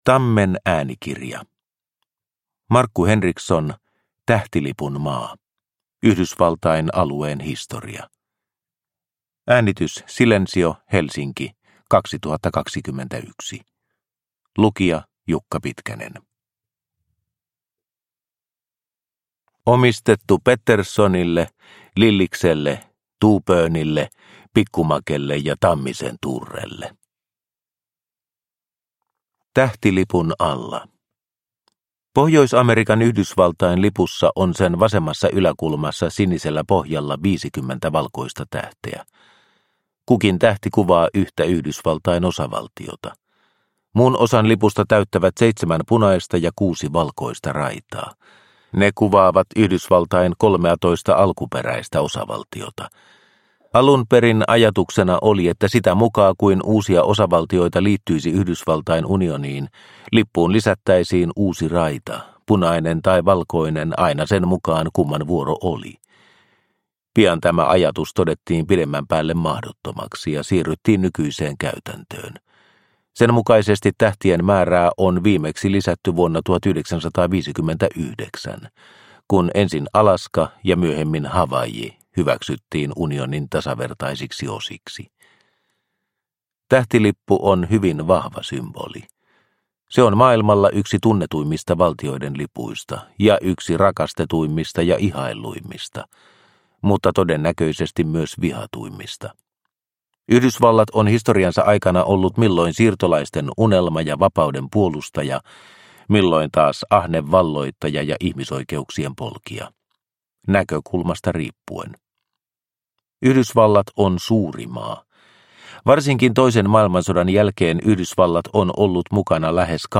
Tähtilipun maa – Ljudbok – Laddas ner